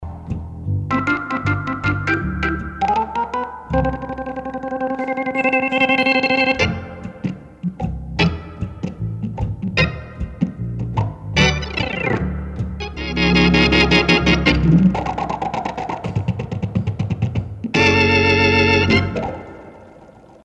evocative writing style and commanding vocal gift